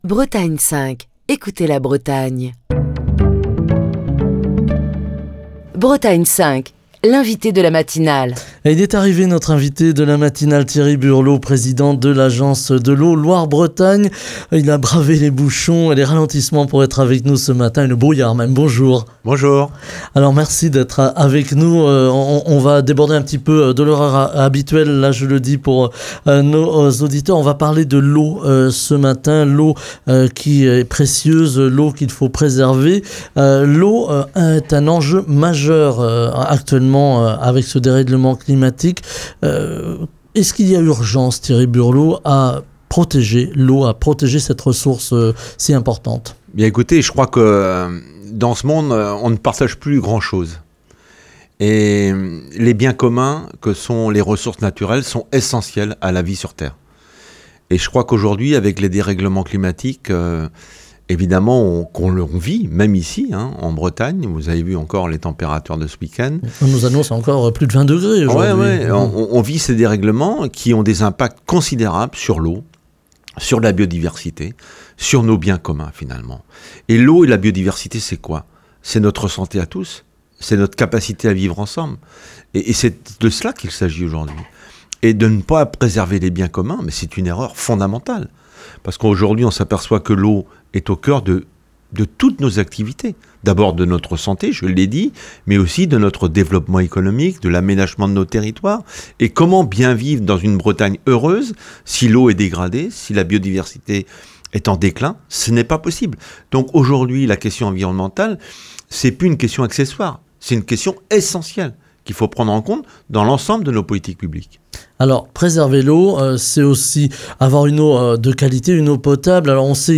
Même en Bretagne les pénuries d'eau, liées au changement climatique, ne sont pas impossibles, et posent la question du stockage, de la gestion de l'eau et plus largement de l'aménagement du territoire. Comment repenser la politique de gestion et de partage de ce bien commun pour éviter à l'avenir une bataille de l'eau. Ce lundi, nous parlons de l'eau avec Thierry Burlot, président de l’Agence de l’eau Loire-Bretagne, qui est l'invité de Bretagne 5 Matin.